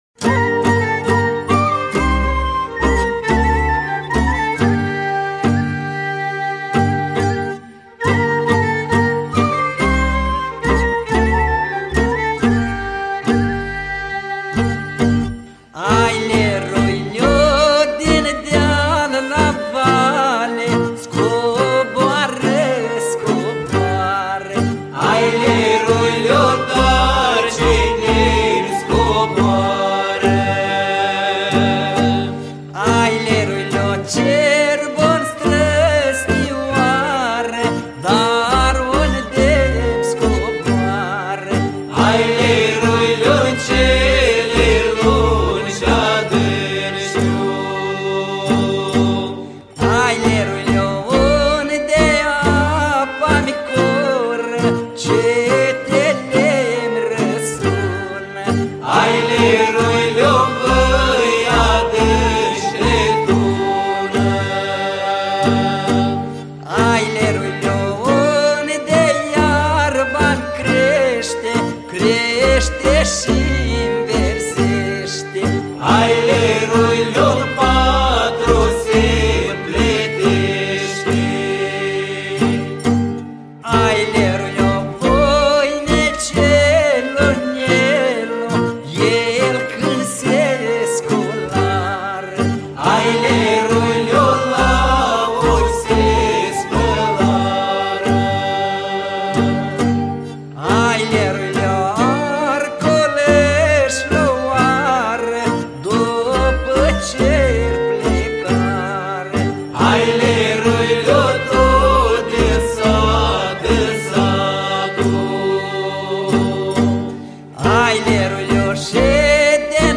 Data: 12.10.2024  Colinde Craciun Hits: 0